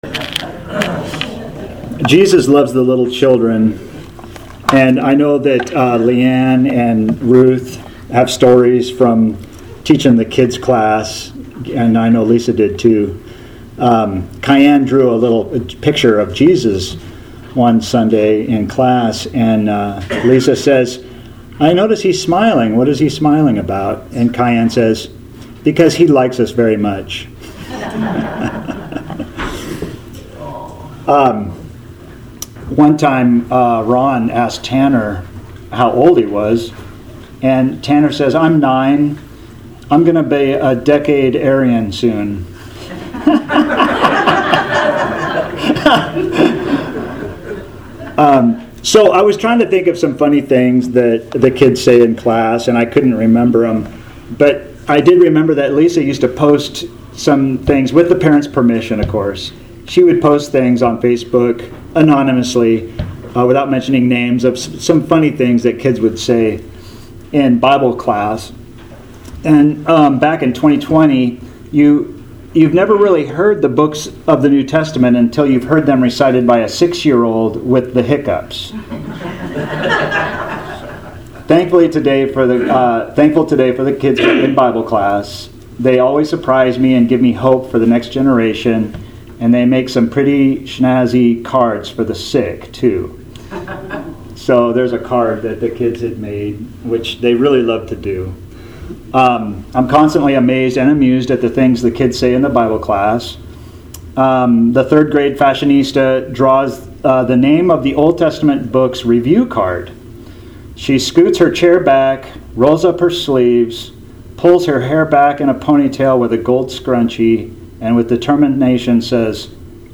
Author jstchurchofchrist Posted on July 25, 2024 July 25, 2024 Categories Sermons Tags Jesus , Luke - Gospel For All